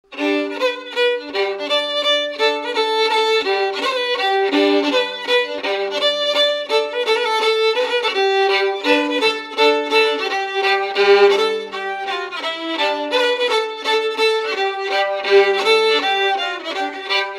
Résumé instrumental
danse : mazurka
Enquête Arexcpo en Vendée
Pièce musicale inédite